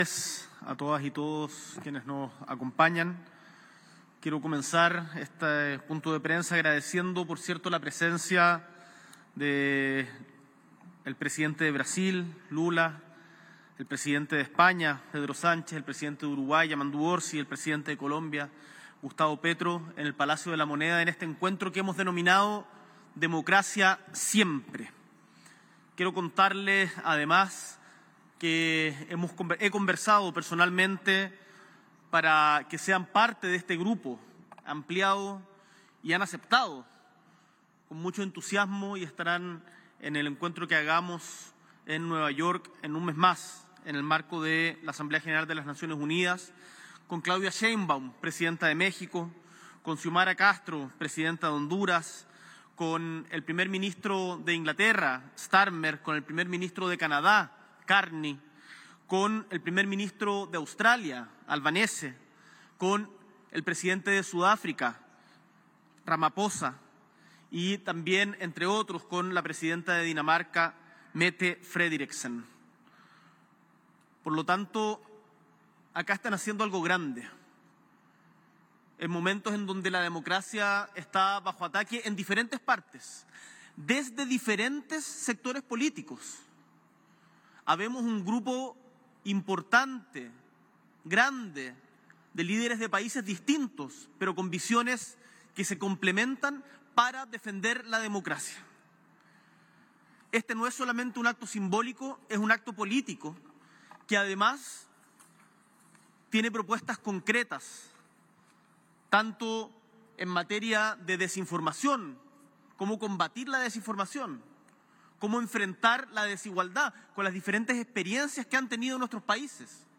Declaración de los Presidentes de Chile, Brasil, España, Uruguay y Colombia tras la reunión de Alto Nivel "Democracia Siempre"